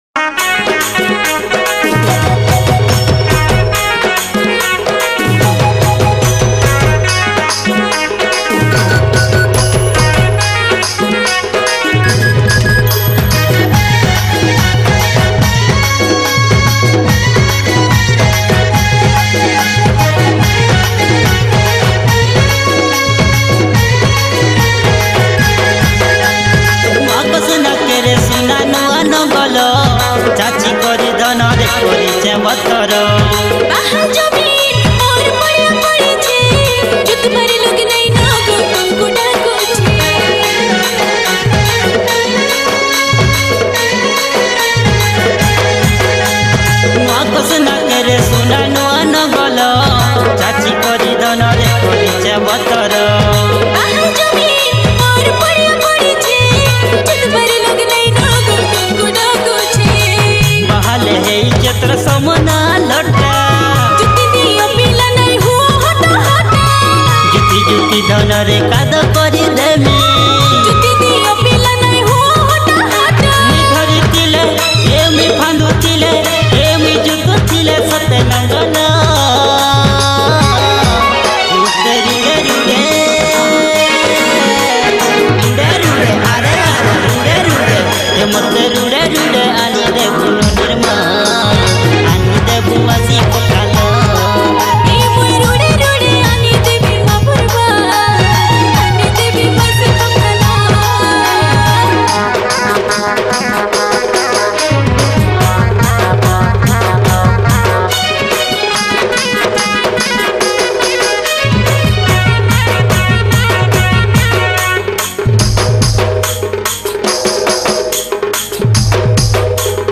Sambalpuri New Flok Song